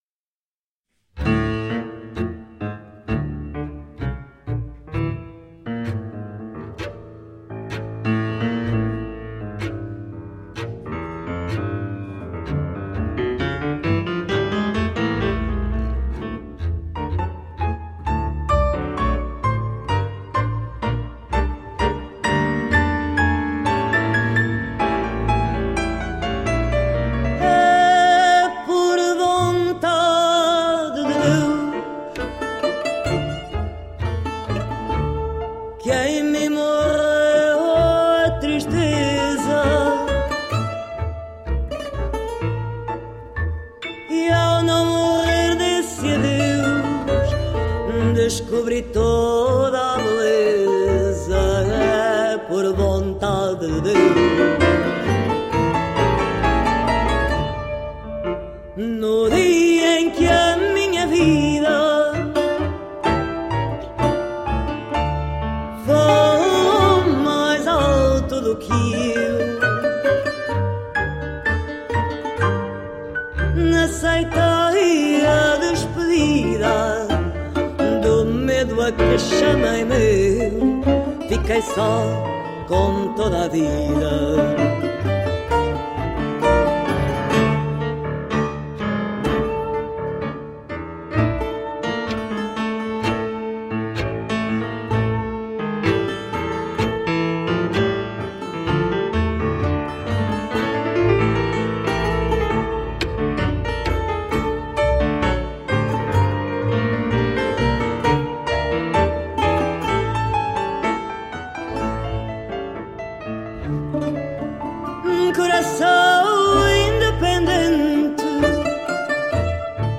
smoky, passionate voice
Tagged as: World, Latin